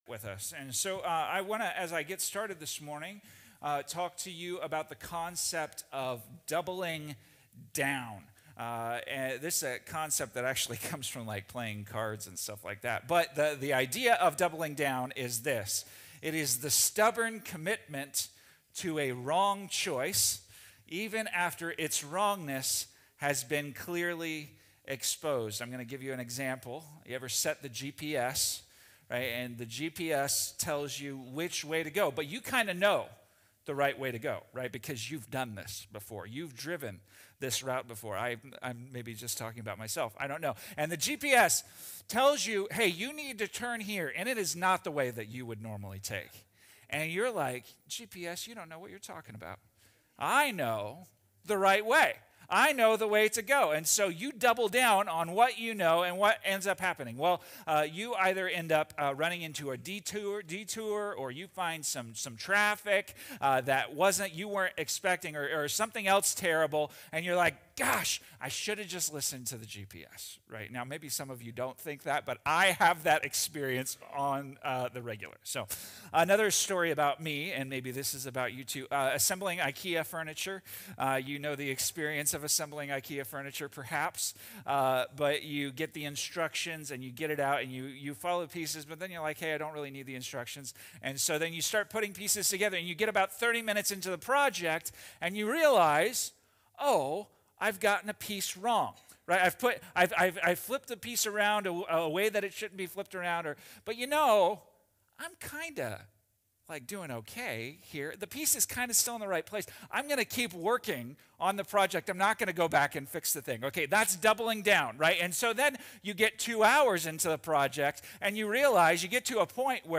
Yet in the middle of their failure, God provided a surprising way of rescue—a bronze serpent lifted on a pole. In this sermon, we’ll explore: Why impatience is more dangerous than we think How Israel’s first corporate confession of sin changed everything Why the bronze serpent points us to Jesus lifted up on the cross (John 3:14–16) What it really means to come to “the end of yourself” and find hope 👉 Whether you’ve been a Christian for years or are just beginning to ask questions about faith, this message will challenge you to stop doubling down, confess, and look to Jesus for life.